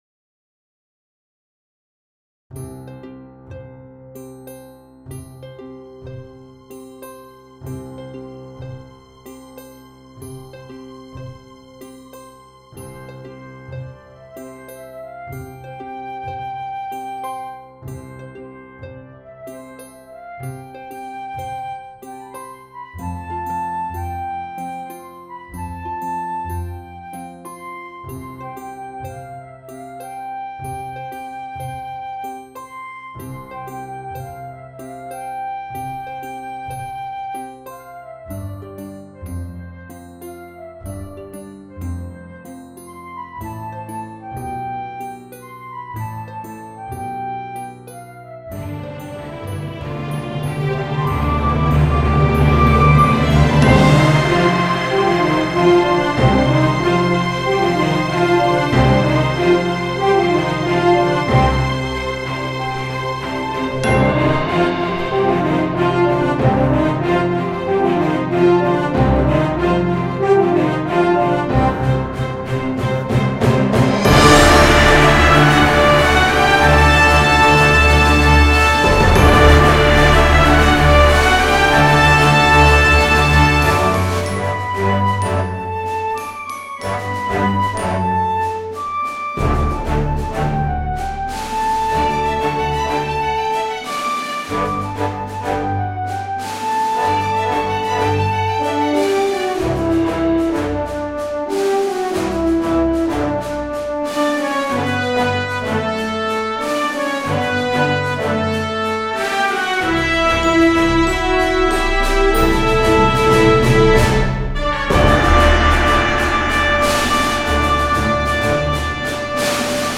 Flute
Oboe
Clarinet
Trumpet
Horn
Low Brass
Violin 1 & 2
Viola
Cello
Piano
Triangle/Bells
Cymbals/Gong
Snare Drum
Bass Drum
Timpani
Harp